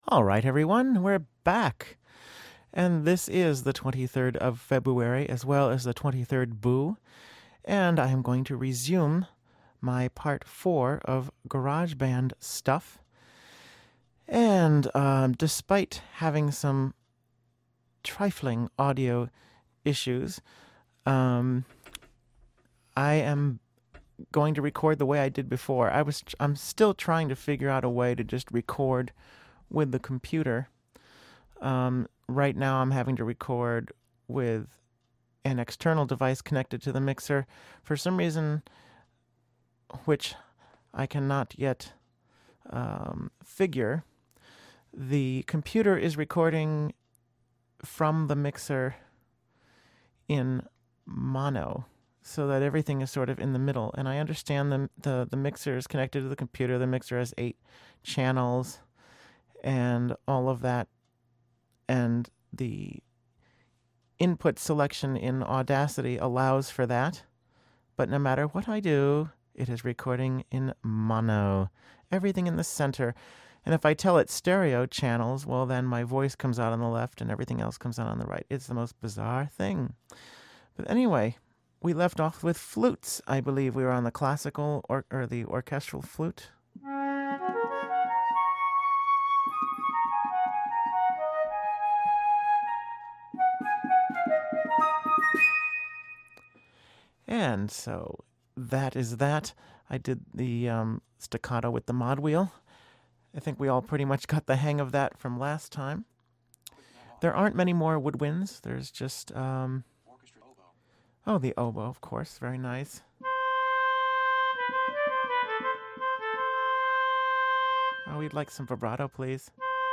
Here is the final installment of GarageBand sounds. There are still more which I haven't covered, but I think this gives a pretty good overview of what some of the best sounds are.